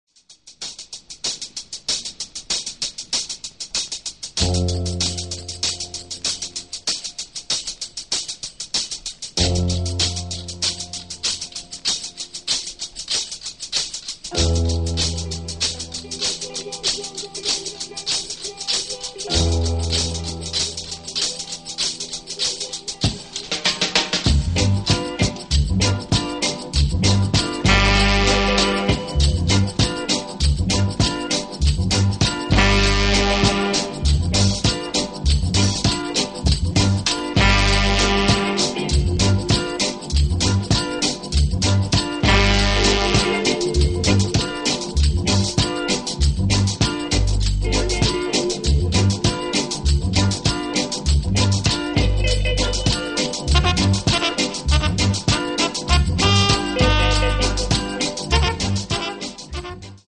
Two blazing Jamaican funk numbers
raw tracks
a nice island flavored take